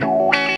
GTR 43 F#M.wav